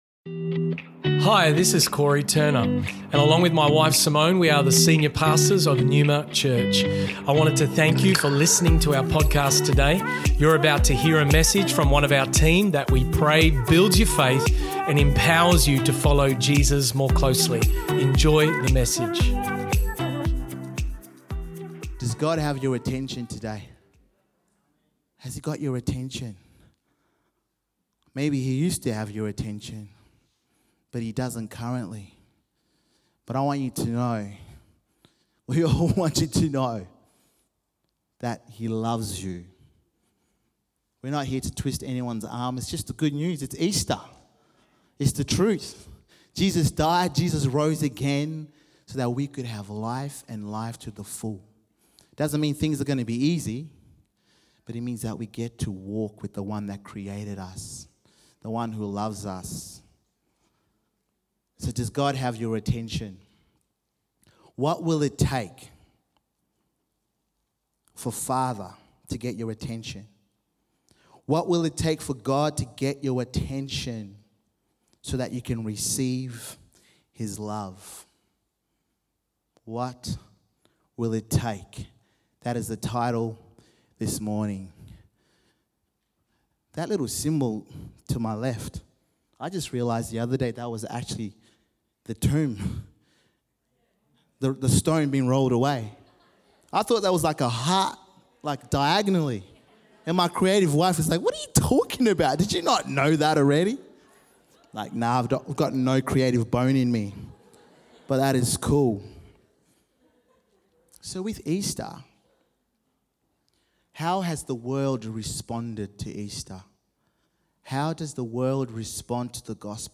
Neuma Church Melbourne South Originally Recorded at the 10am Service on Easter Sunday 9th April 2023.&nbsp